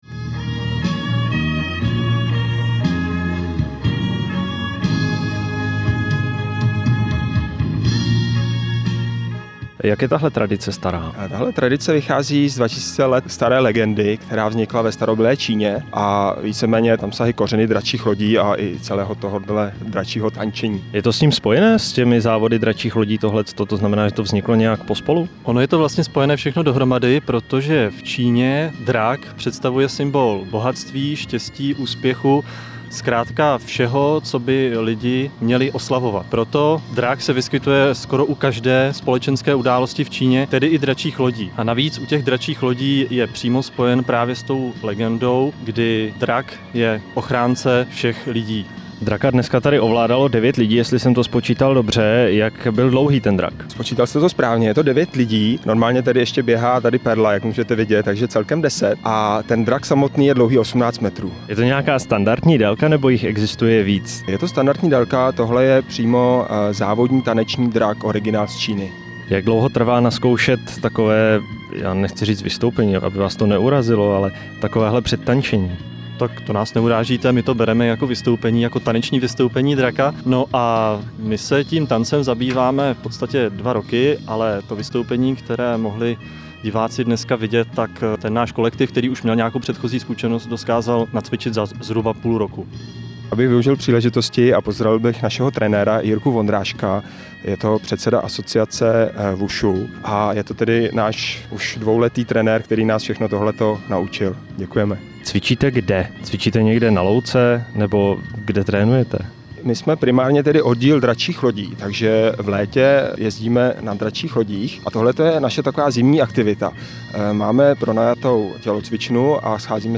Rozhlasová reportáž o dračím tanci pro Český rozhlas ze závodů v Pardubicích